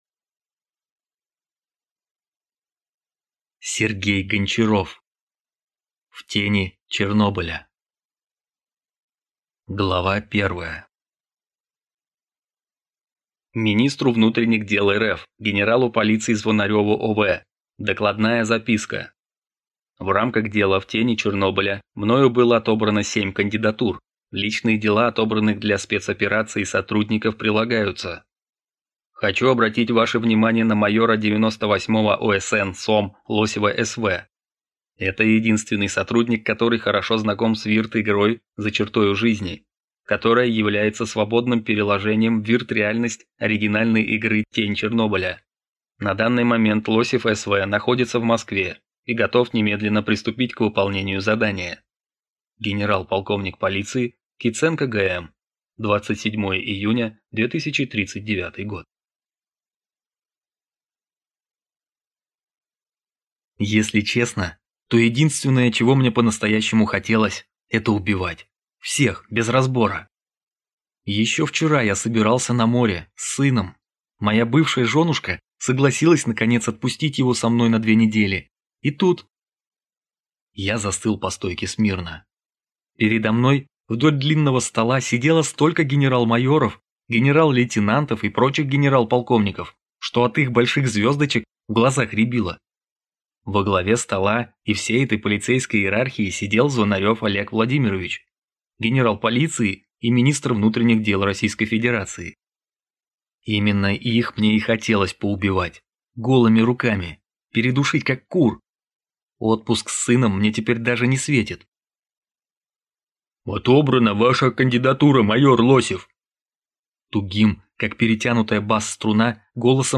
Аудиокнига В тени Чернобыля | Библиотека аудиокниг